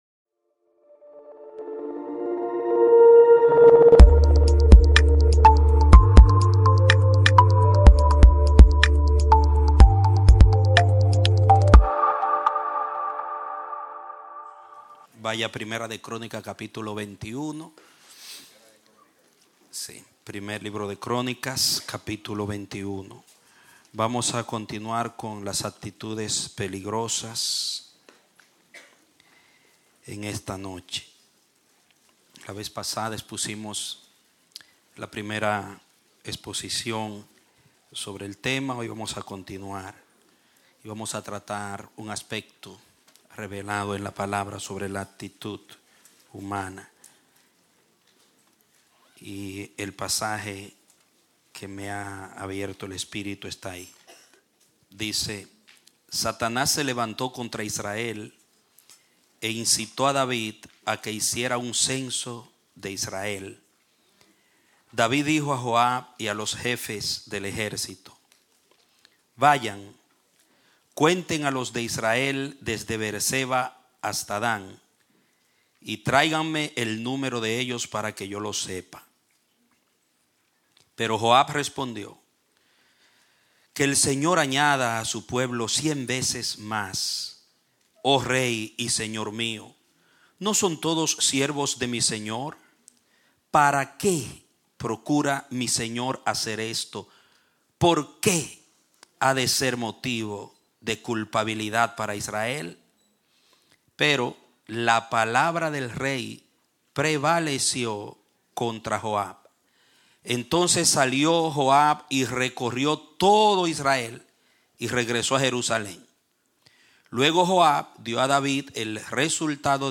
Un mensaje de la serie "Actitudes Peligrosas."